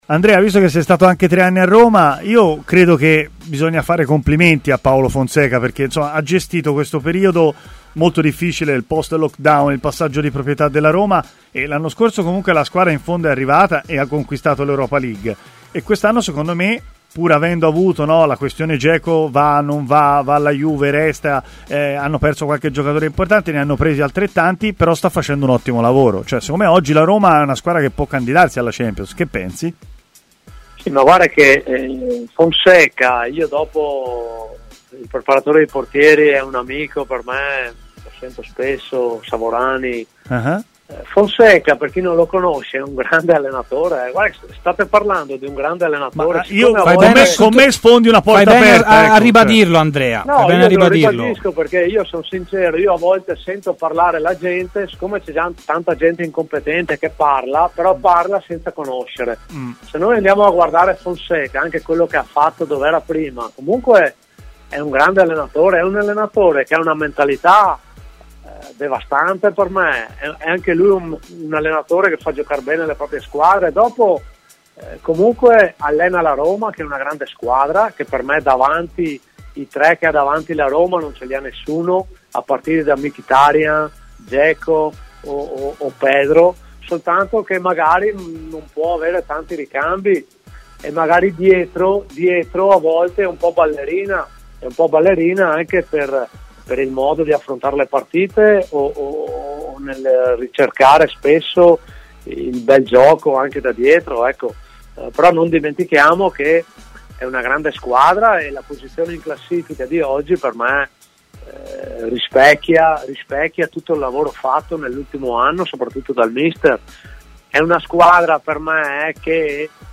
ha parlato a Stadio Aperto, trasmissione di TMW Radio